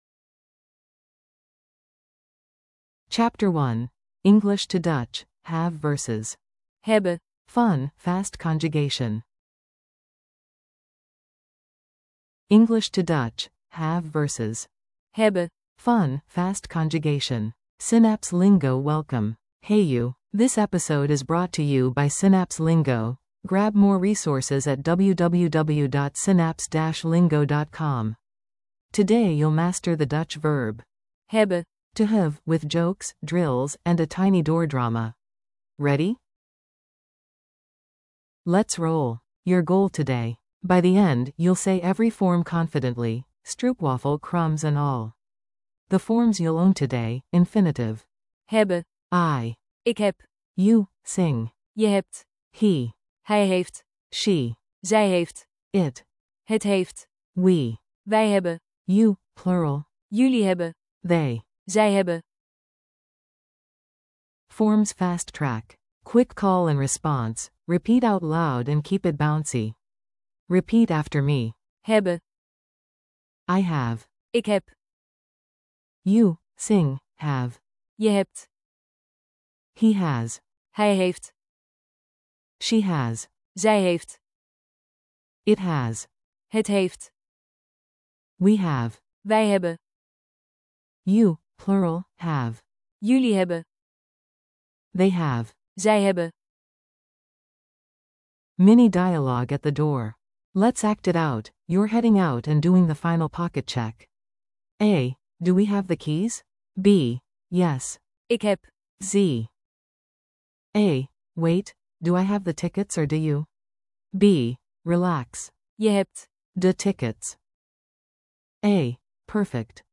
Audio for repeating & practicing
• ✔ eBooks + Audiobooks complete